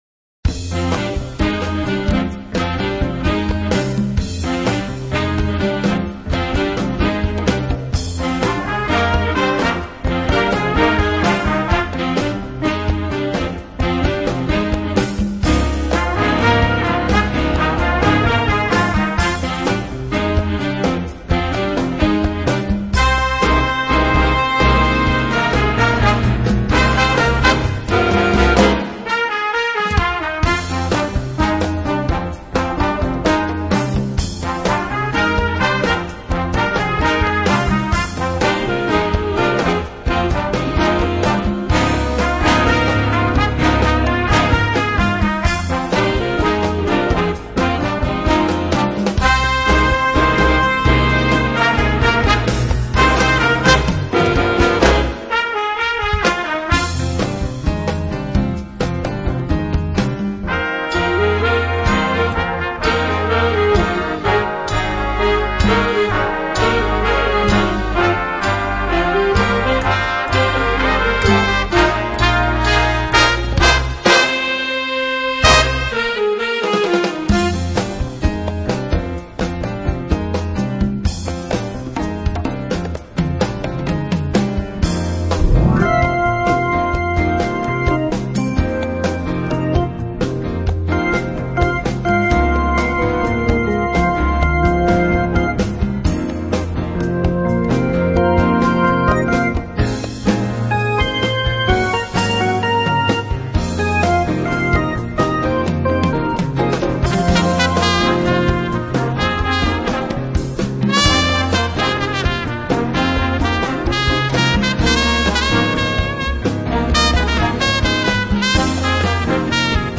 Ensemble and Handbell
Handbells 3-5 Octave